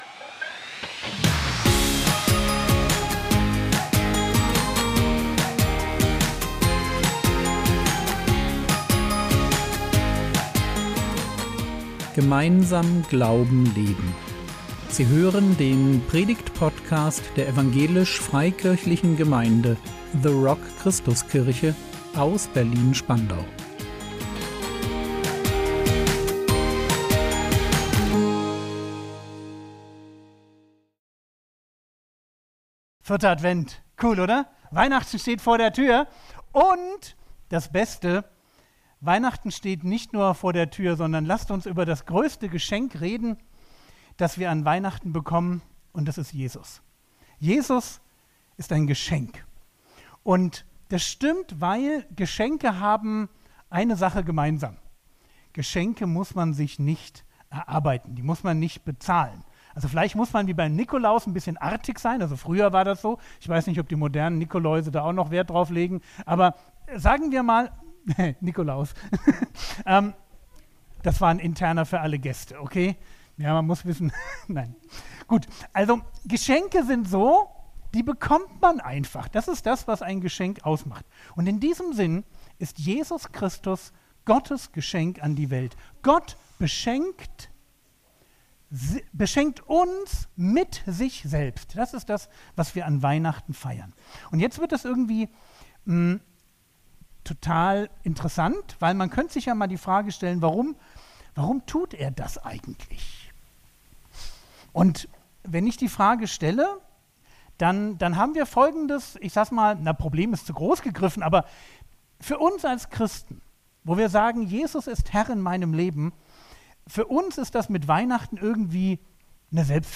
Impuls